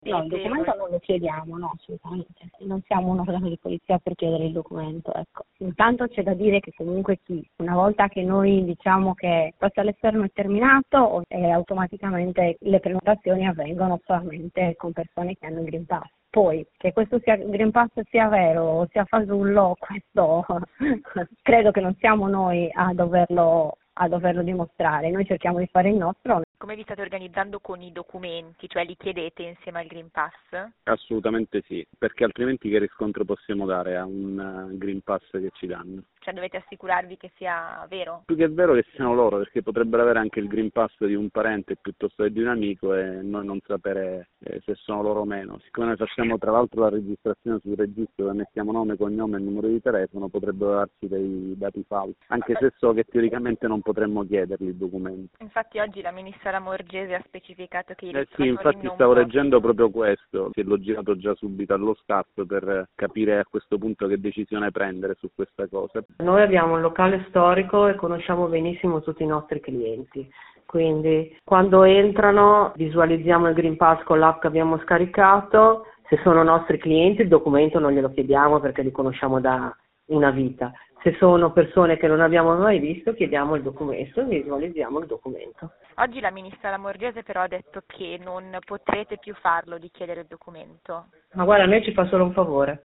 Li sentiamo: